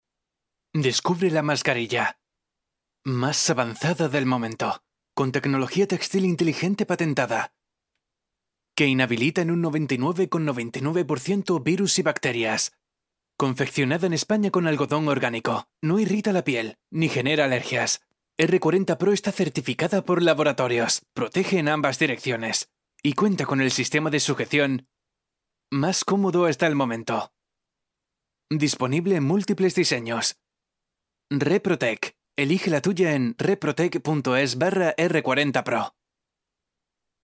标签： 稳重
配音风格： 稳重 抒情 自然 大气 沉稳 激情 伤感